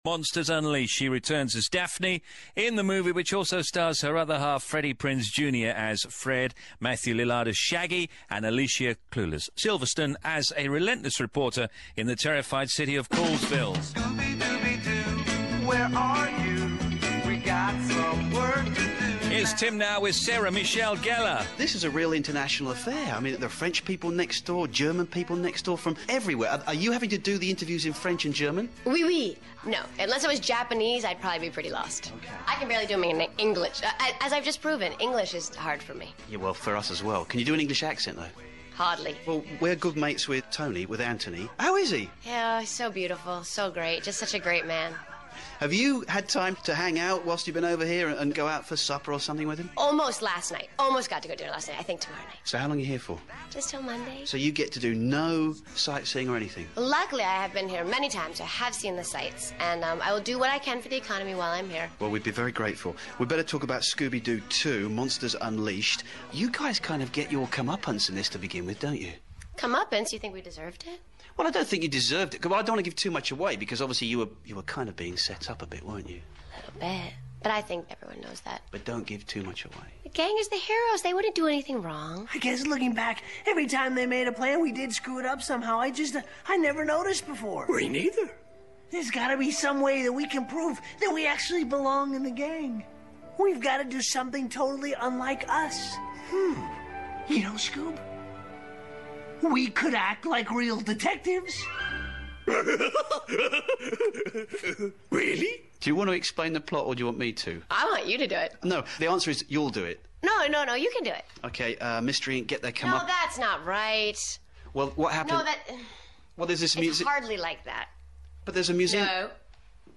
:: Interview :: Interview
smg_bbcradio2.mp3